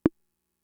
Index of /kb6/Fricke_MFB-522/CONGAS
Conga (21).WAV